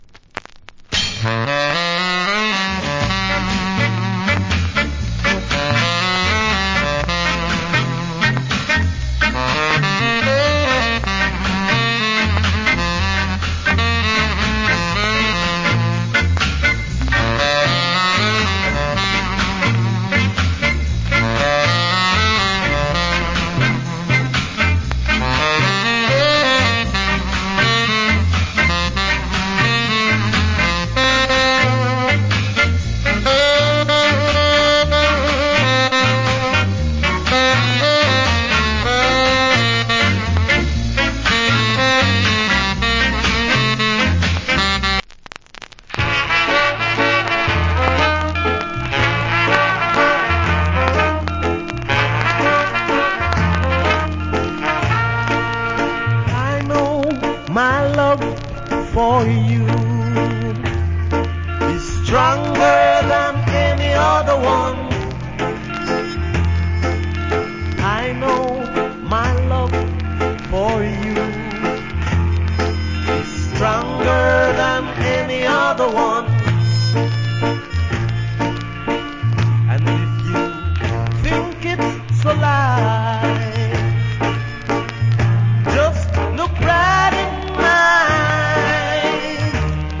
Great Ska Inst.